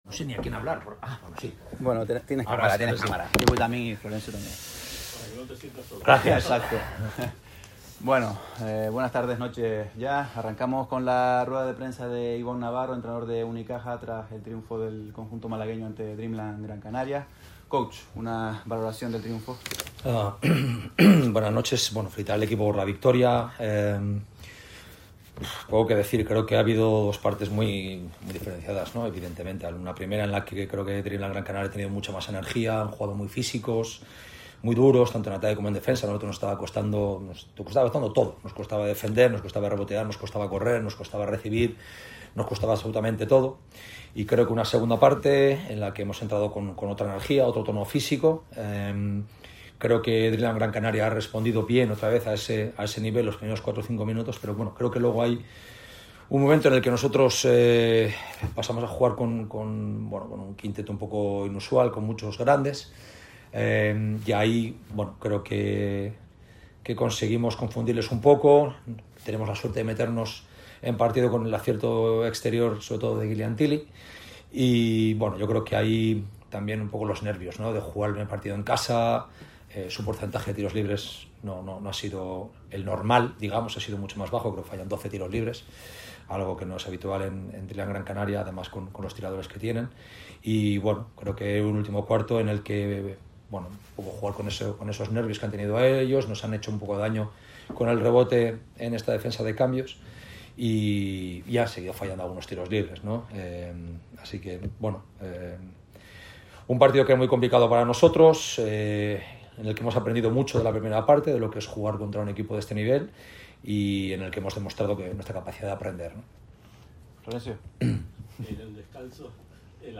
Aquí las palabras del técnico vitoriano al término del encuentro.